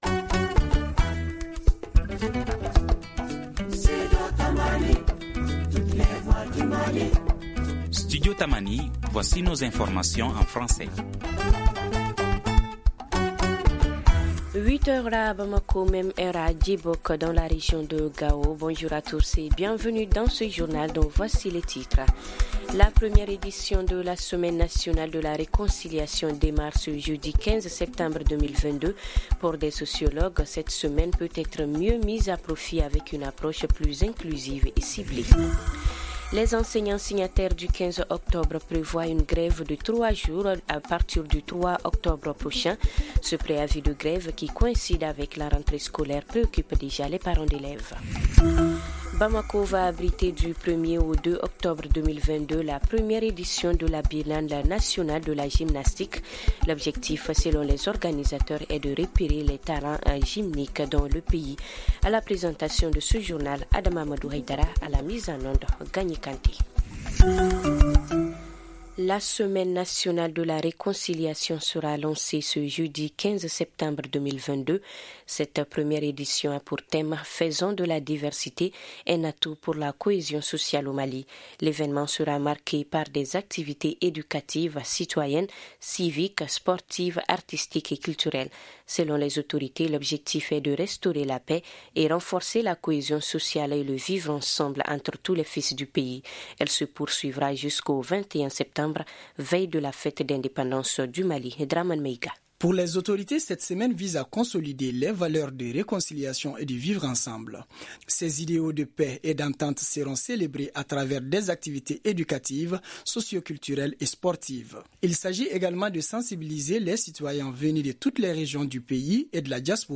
Ci-dessous, écoutez le développement de ces titres dans nos journaux en français et en langues nationales :